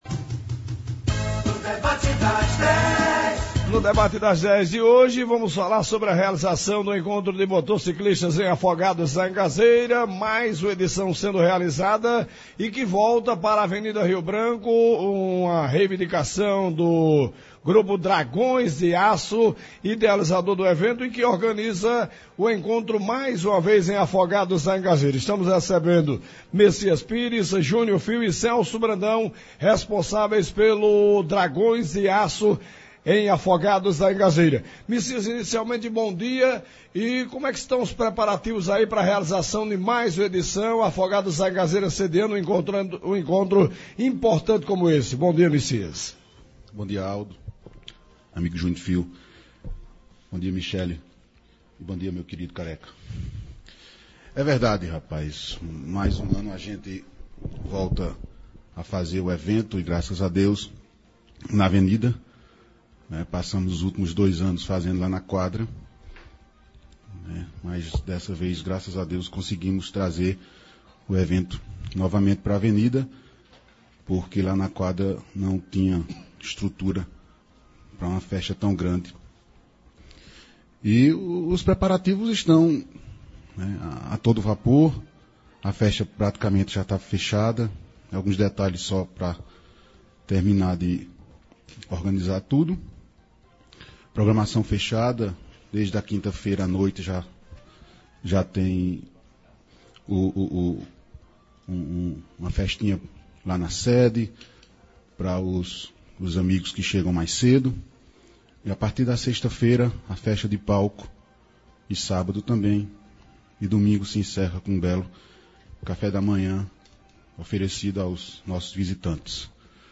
Os organizadores se mostraram muito animados e a expectativa e que a cidade fique cheia já que as pousadas do município já se encontram sem vagas, também informaram que já foram alugadas dezesseis casas e a expectativa é de alugar mais quinze. O novo horário para o encontro que é padrão para todos os eventos realizados na Avenida Rio Branco também foi comentado durante o Debate, sendo visto como positivo pelos organizadores.